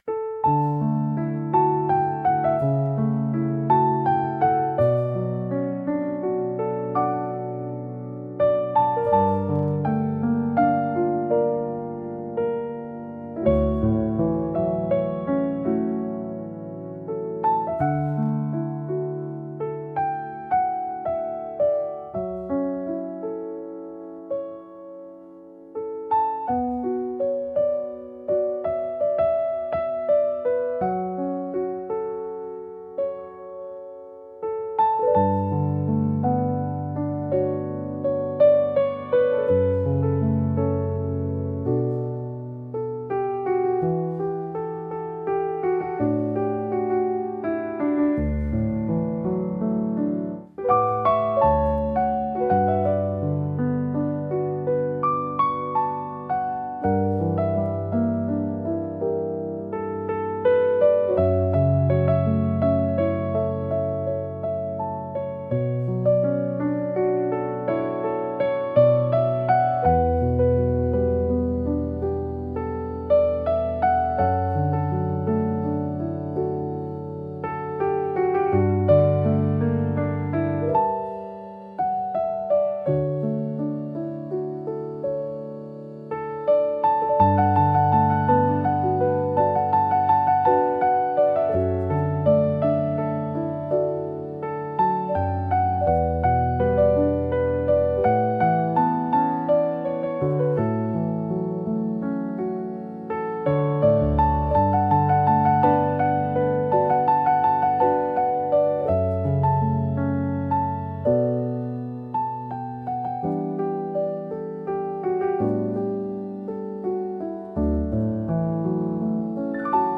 聴く人にやすらぎと温かみを提供し、心を穏やかに整える効果があります。繊細で情感豊かな空気を醸し出すジャンルです。